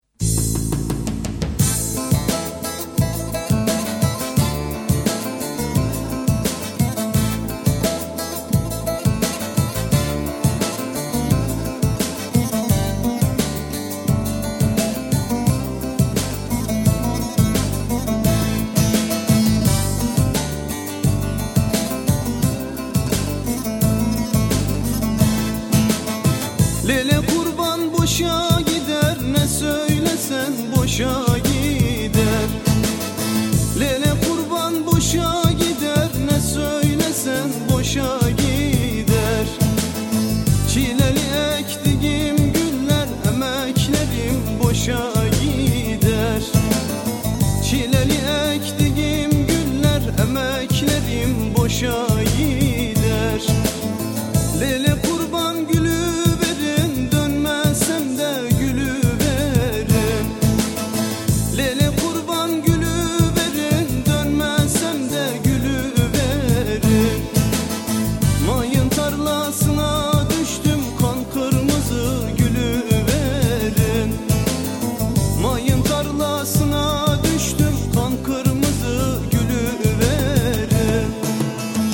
ژانر: پاپ ترکی